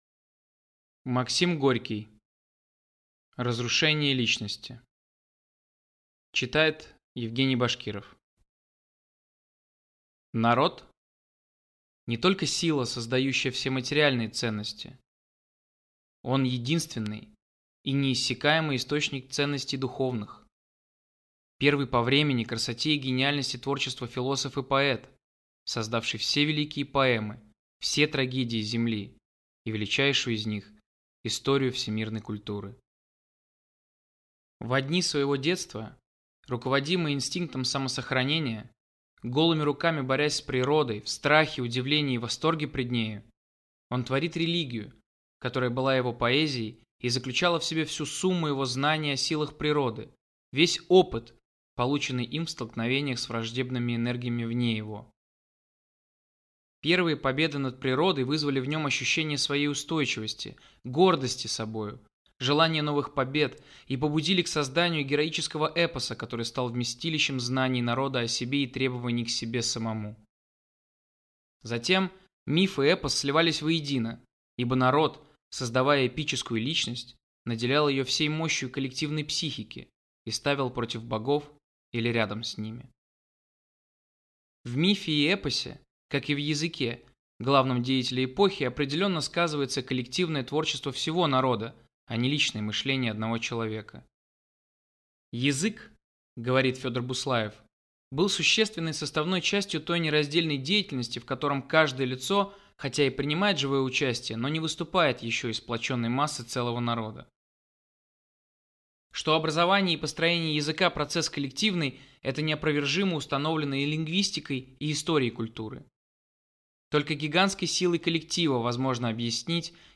Аудиокнига Разрушение личности | Библиотека аудиокниг